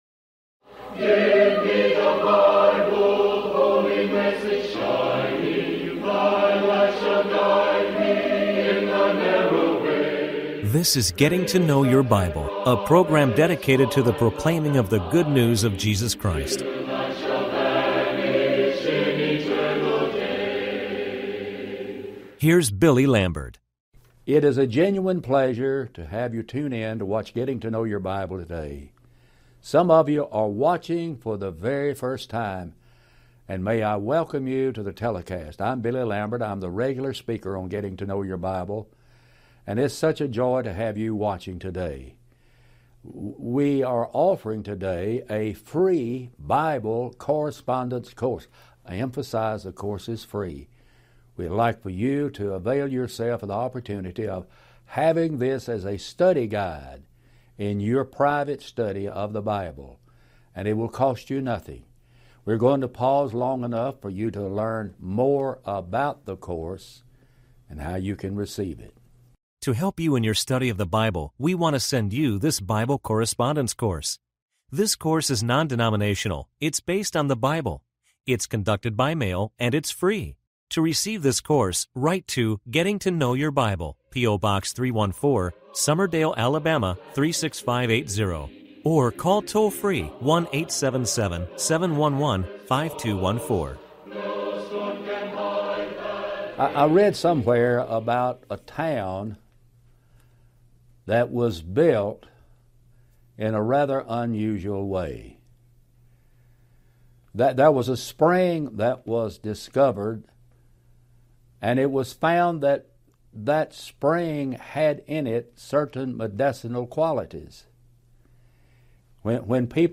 Talk Show Episode, Audio Podcast, Getting To Know Your Bible and Back To Spring - ep1473 on , show guests , about Back To Spring, categorized as History,Kids & Family,Religion,Christianity,Society and Culture